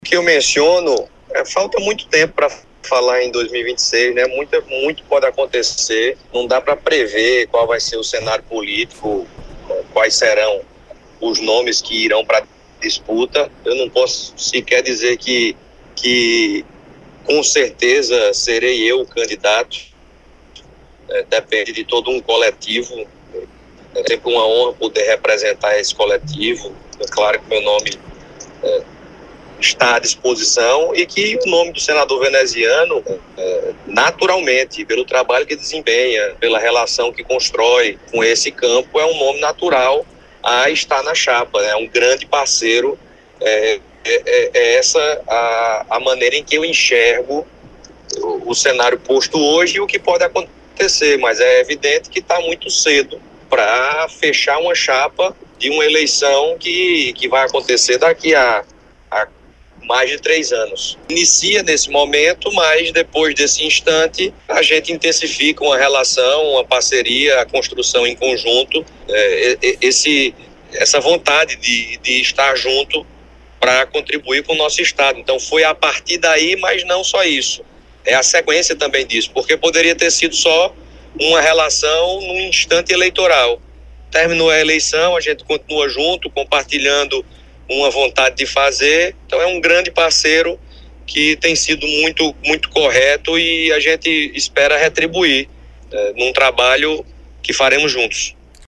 O comentário de Pedro foi registrado pelo programa Correio Debate, da 98 FM, de João Pessoa, nesta quinta-feira (13/07).